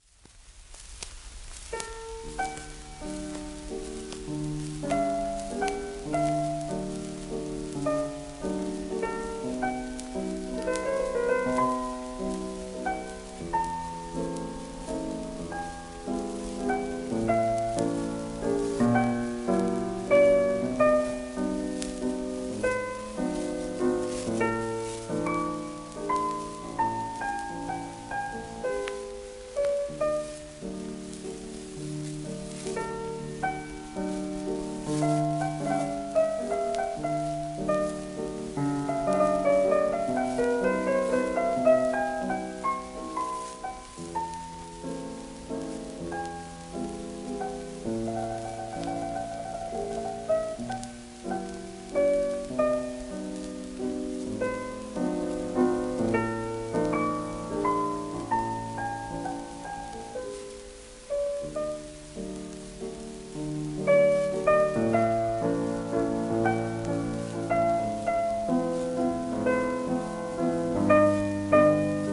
1920年代後半録音